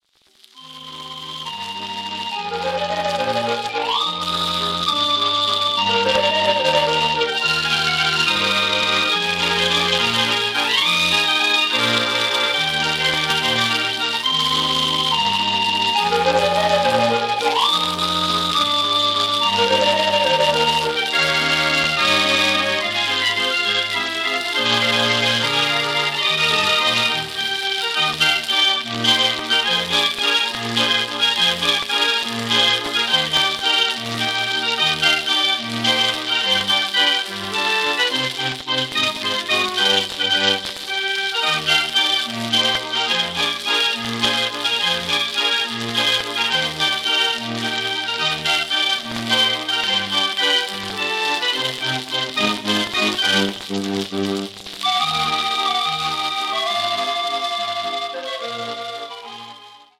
Formaat 78 toerenplaat, schellak